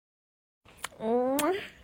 Zesty Kiss Sound Button - Free Download & Play